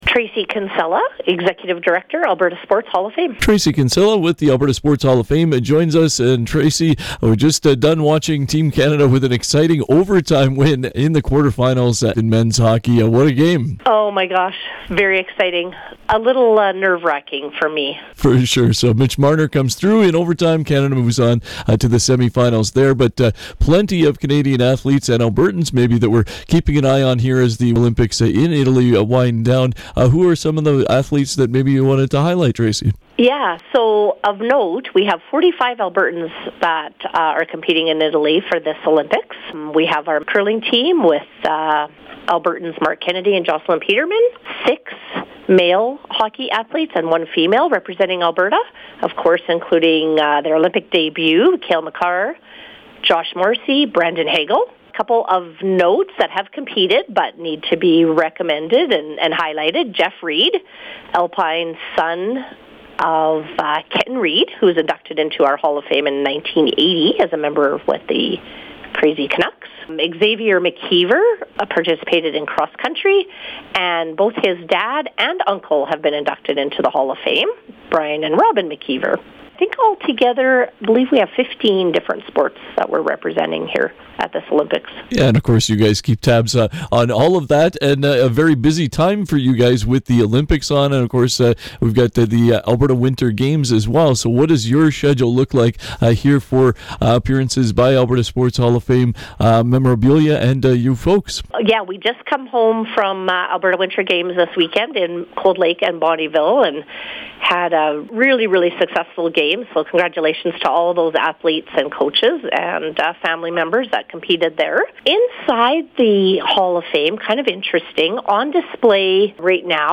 Community Hotline conversation